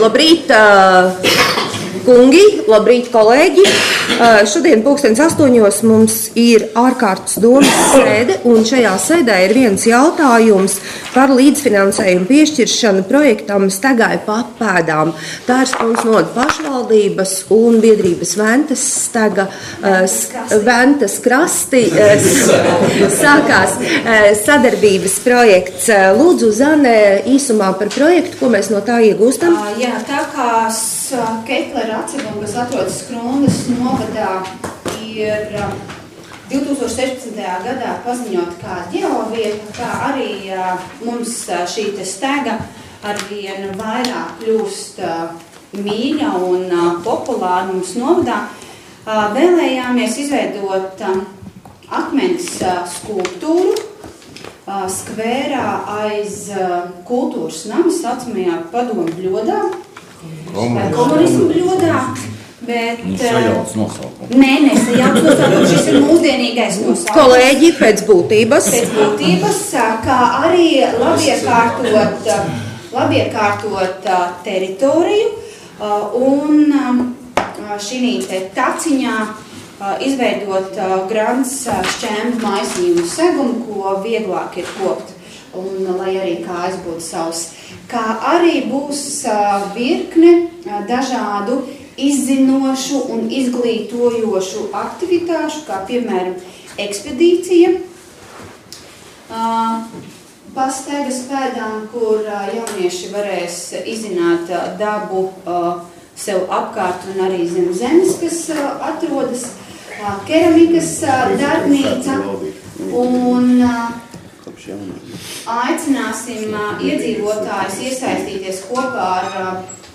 Skrundas novada domes 2016. gada 18. februāra ārkārtas sēde